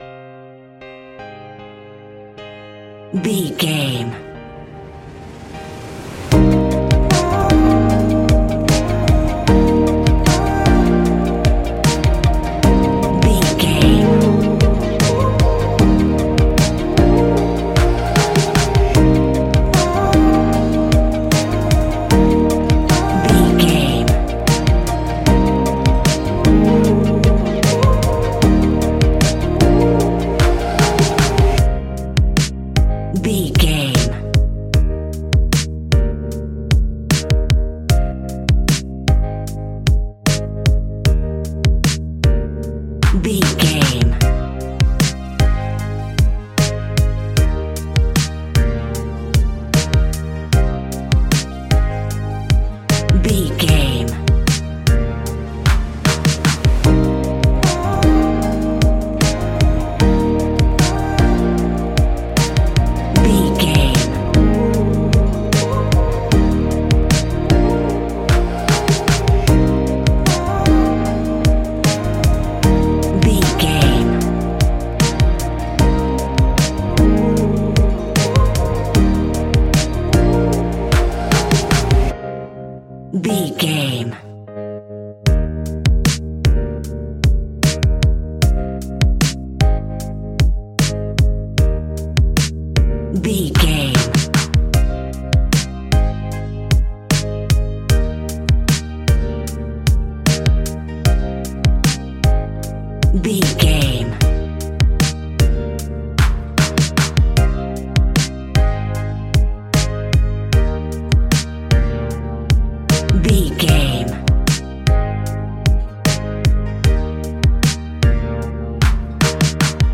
Ionian/Major
D♯
ambient
electronic
chill out
downtempo
synth
pads